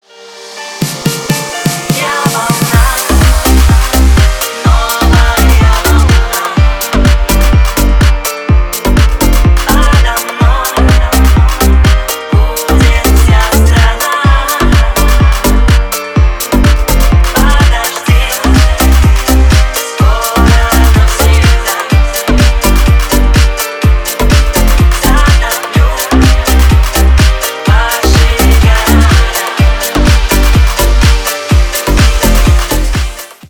# Ремикс # Поп Музыка # Электроника
тихие